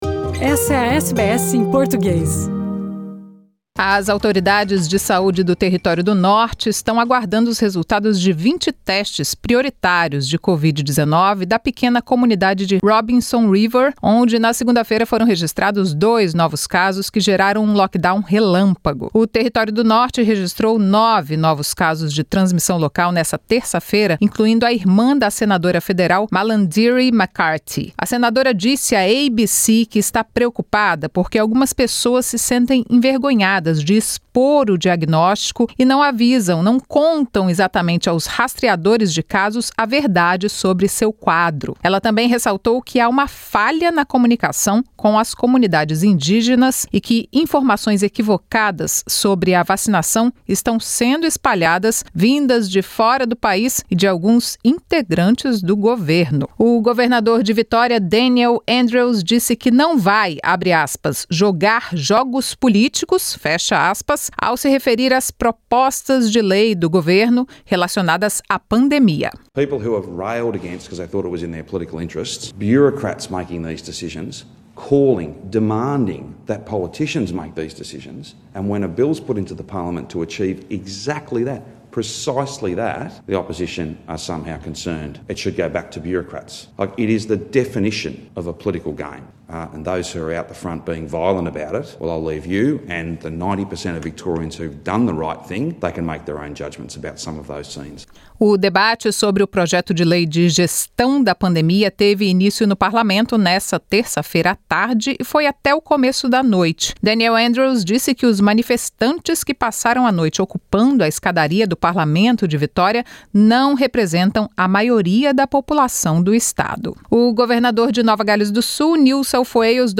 Tensão na fronteira da Polônia e Bielorrúsia pelo fluxo de imigrantes no local. As notícias da Austrália e do Mundo da Rádio SBS para esta quarta-feira.